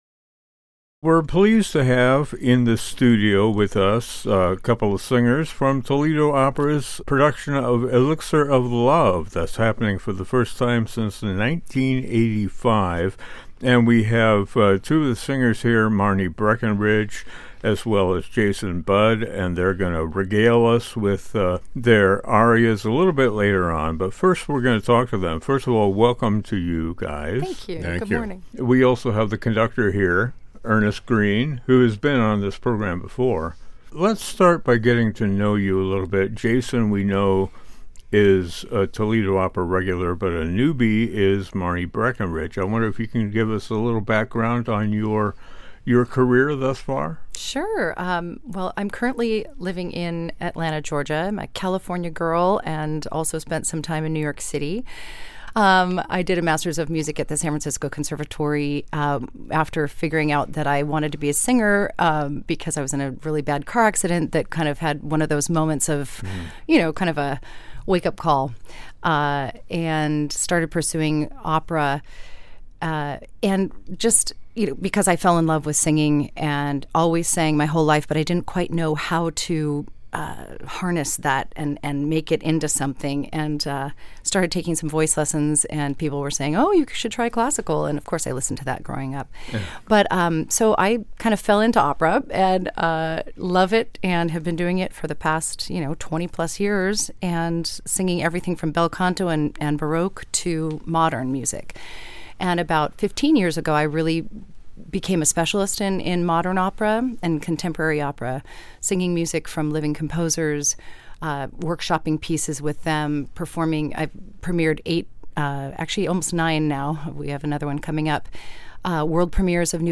We're joined by creatives from Toledo Opera for a discussion (and performance) of the opera "The Elixir of Love" by Gaetano Donizetti.